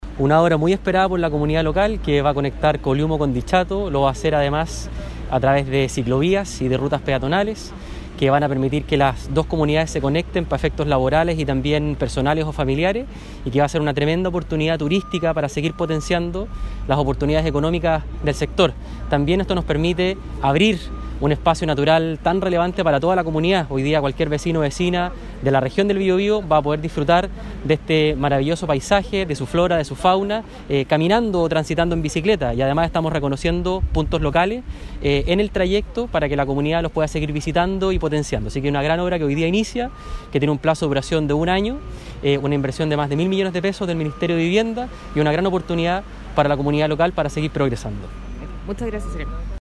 En ese sentido, el seremi de Vivienda y Urbanismo, Sebastián Abudoj señaló que efectivamente es “una obra muy esperada por la comunidad local que va a conectar Coliumo con Dichato, a través de ciclovías y rutas que van a permitir que las dos comunidades se conecten para efectos laborales y también personales y familiares”.
cuna-02-coliumo-dichato-seremi.mp3